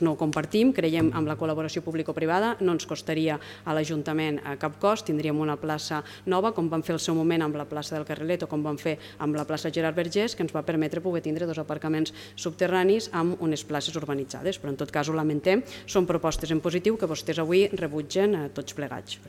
La portaveu de Junts per Tortosa, Meritxell Roigé, ha lamentat que s’hagi rebutjat a un projecte sense cap cost per a l’ajuntament…